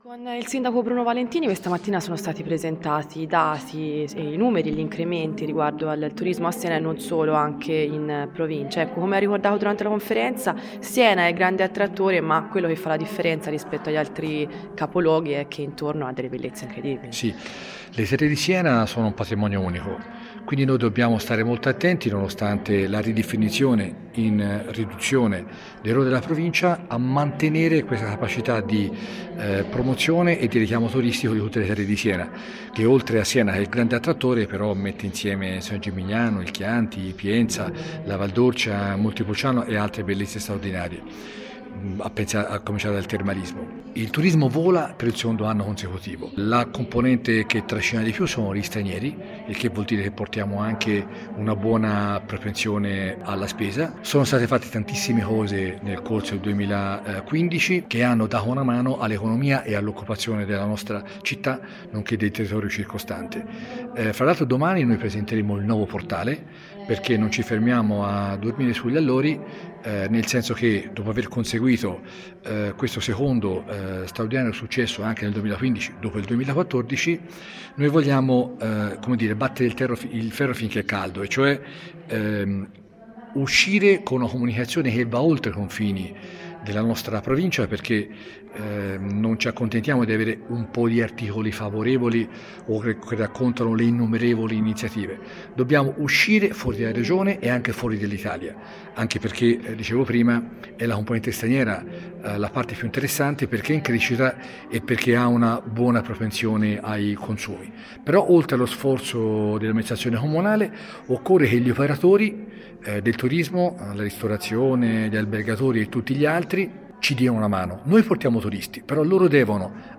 Ascolta l’intervista del sindaco Bruno Valentini